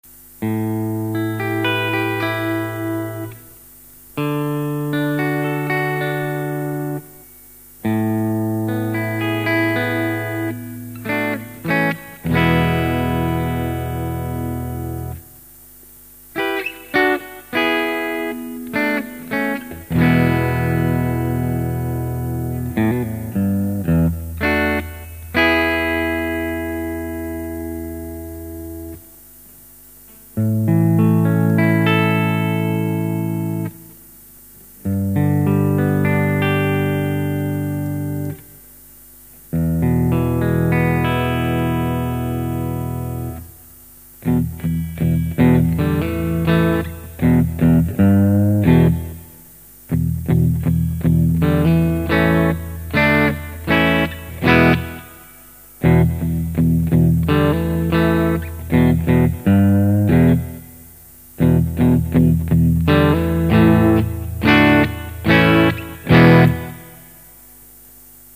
The Manticore offers all the sweetness of a standard vintage Firebird pickup but with a slightly meatier tone and improved definition.
Firebird style mini humbuckers have a unique sound, not only are they bright but they are more focused than other pickups. The manticore has a strong attack, giving it that urgency that was such a big part of the british sounds of the 60's and 70's.
Bridge    Neck